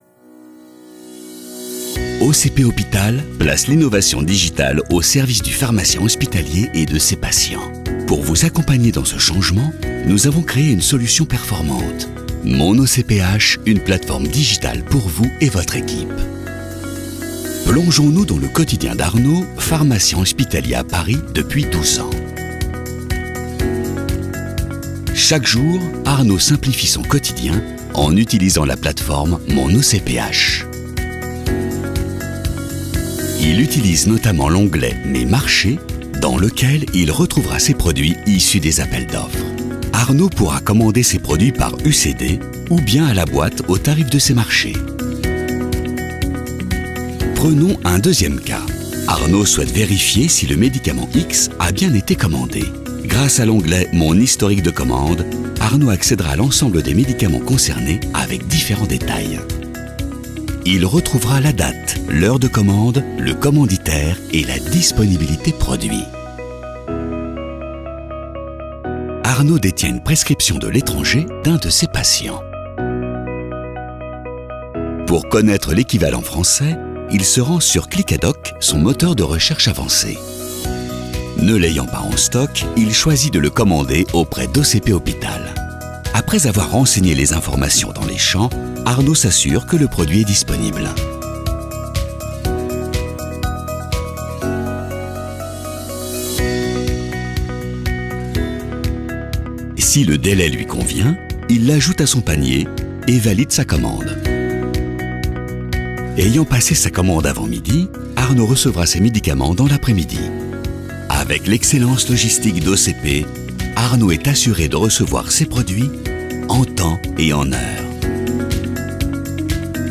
Institutionnel Narrative Tape